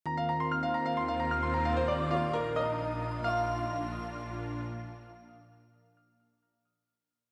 Longhorn 2000 - Log On.wav